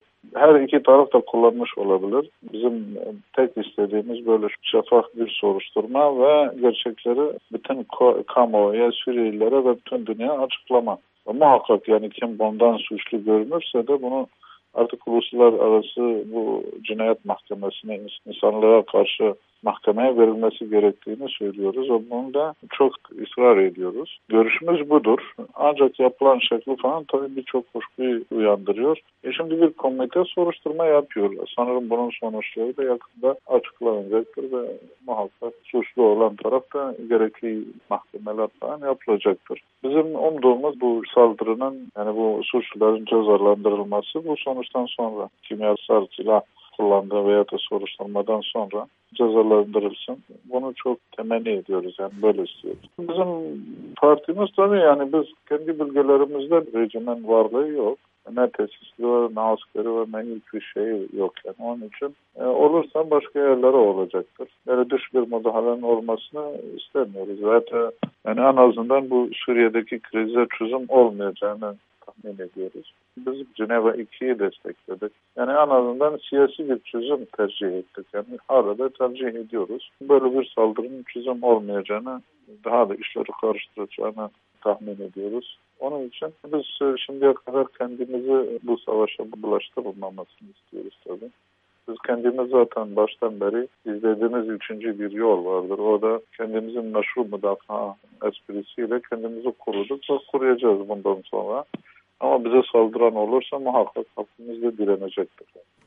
Amerika’nın Sesi’ne Suriye’deki gelişmeleri ve olası müdahaleyi değerlendiren PYD lideri Salih Müslüm kullanıldığı iddia edilen kimyasal silahlarda ‘Özgür Suriye Ordusu’nun parmağı olabilir’ dedi